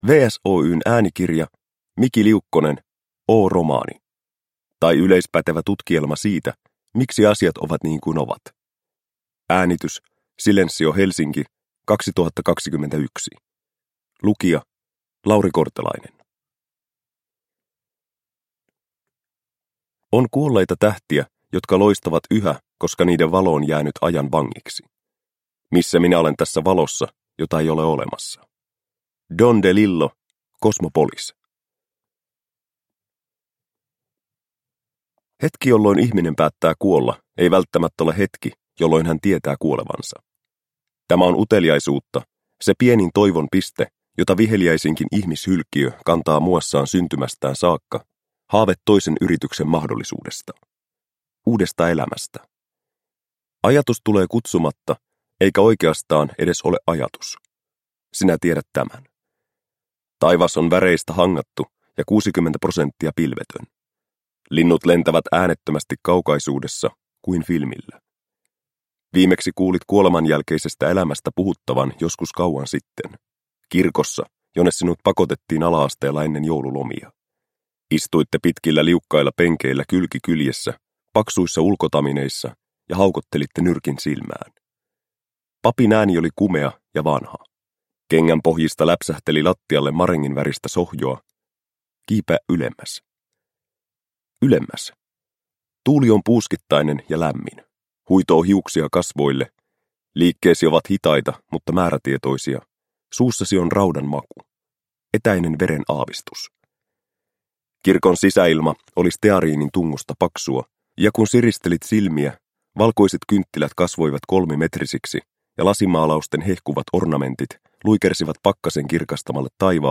O – Ljudbok – Laddas ner